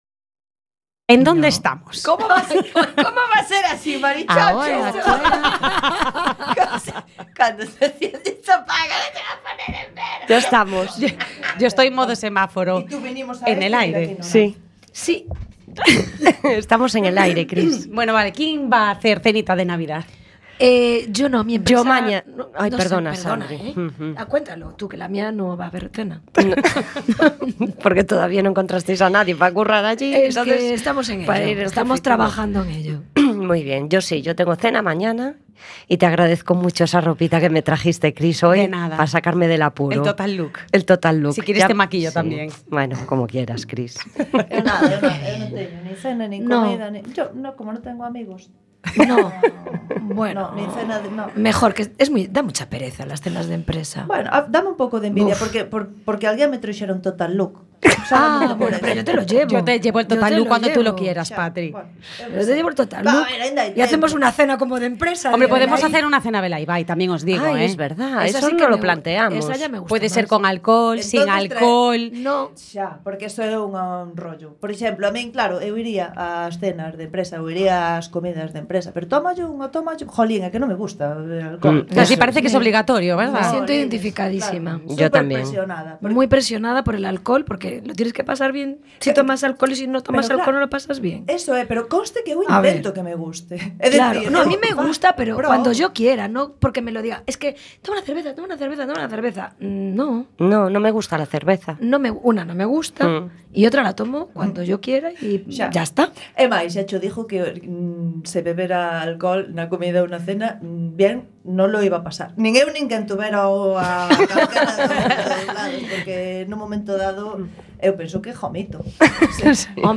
Tertulias sobre temas diversos, xogos, preguntas e experiencias persoais.
Os venres ás 20.00H en directo.